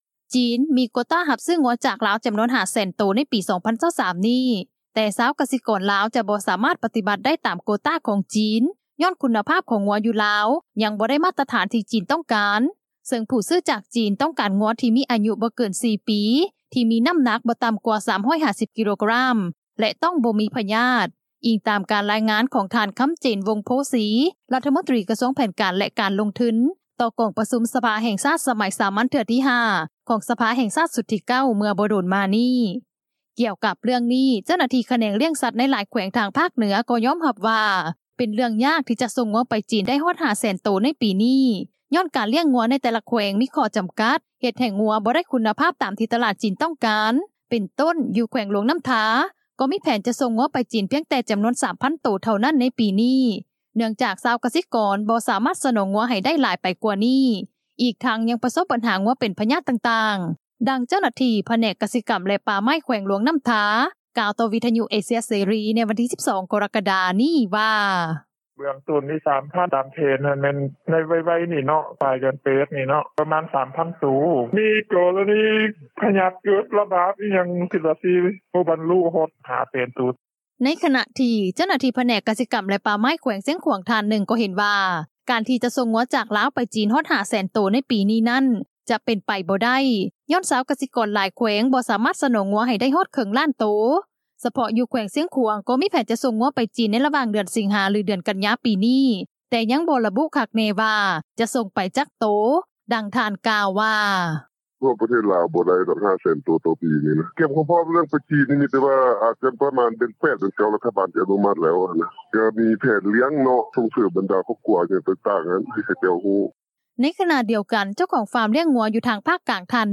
ດັ່ງເຈົ້າໜ້າທີ່ຜແນກກະສິກັມ ແລະປ່າໄມ້ ແຂວງຫຼວງນໍ້າທາ ກ່າວຕໍ່ວິທຍຸ ເອເຊັຽ ເສຣີ ໃນວັນທີ 12 ກໍຣະກະດາ ນີ້ວ່າ:
ດັ່ງຍານາງ ກ່າວວ່າ: